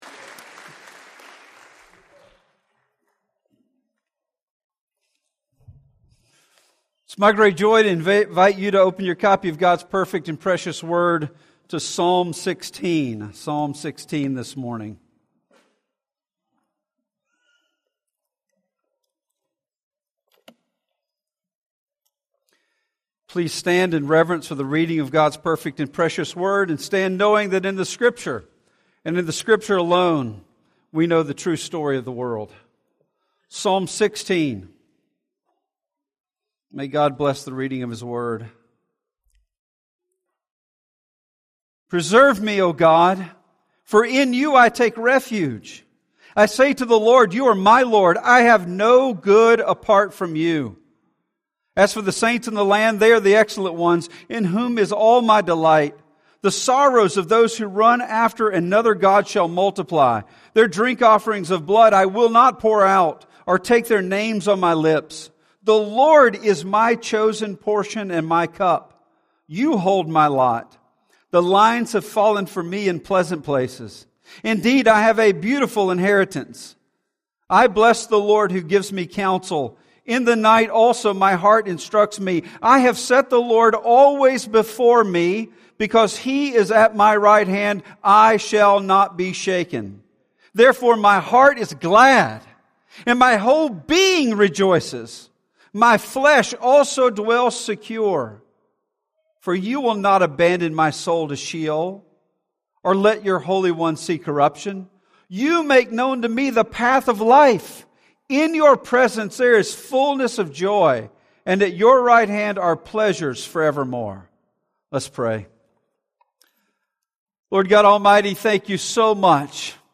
Sermon Audio Sermon Video Sermon Notes Sermon Audio http